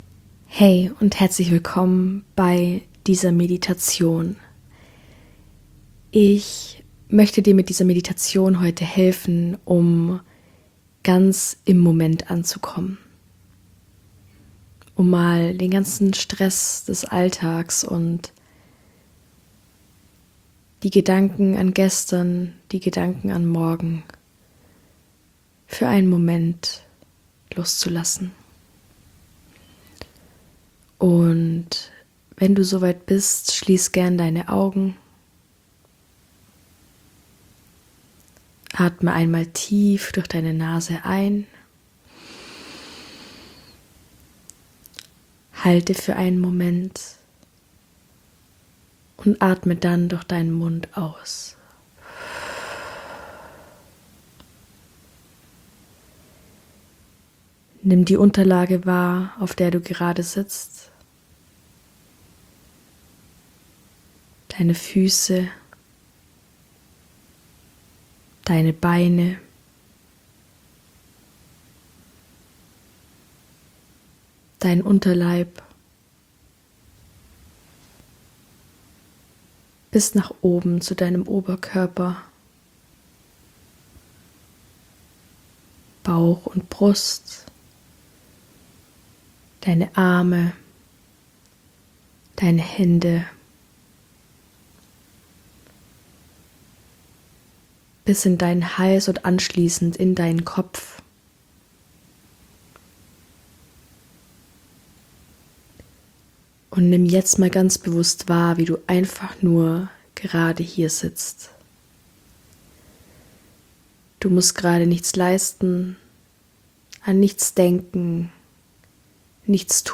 Verbinde dich mit dir selbst und dem Hier & Jetzt - Meditation